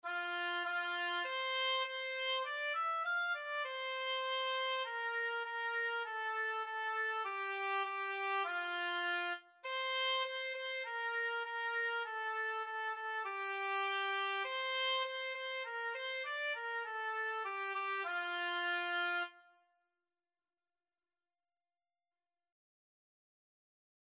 Free Sheet music for Oboe
F major (Sounding Pitch) (View more F major Music for Oboe )
4/4 (View more 4/4 Music)
Moderato
F5-F6
Oboe  (View more Beginners Oboe Music)
Traditional (View more Traditional Oboe Music)